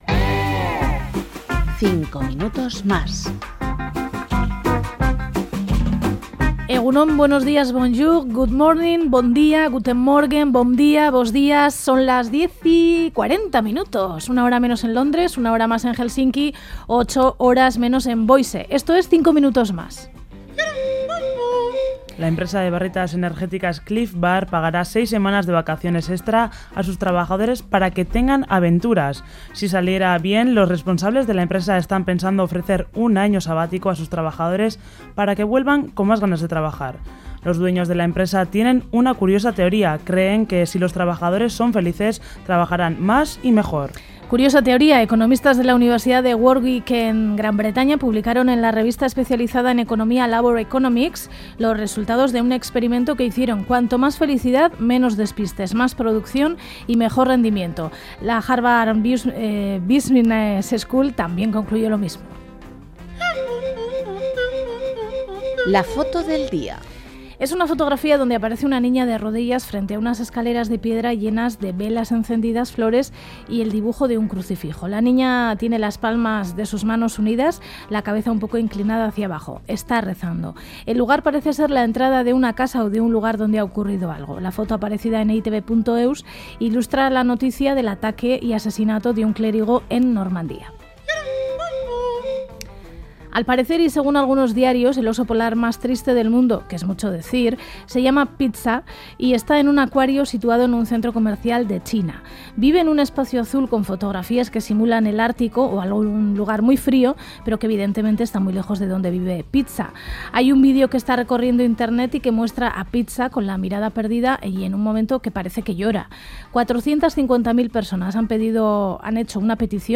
Audio: 5 MINUTOS MÁS es un informativi atípico que trata de mostrar esas otras noticias que no suelen aparecer en los actuales informativos.